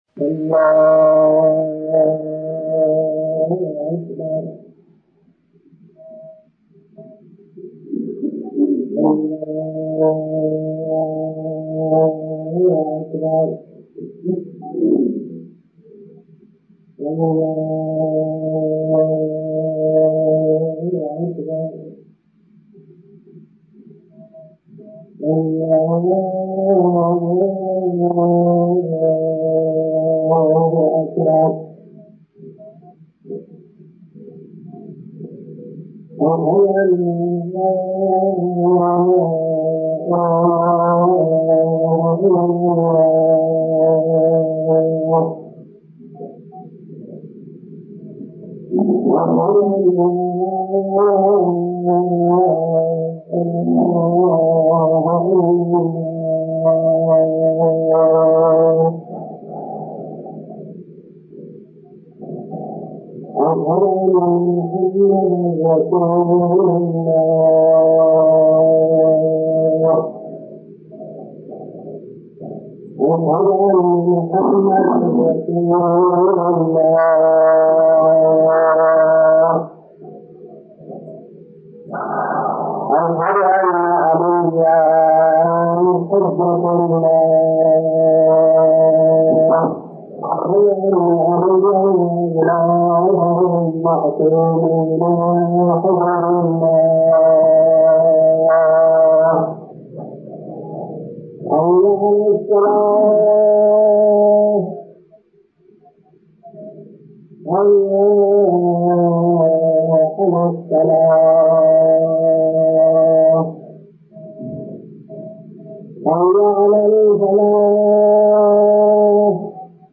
نماز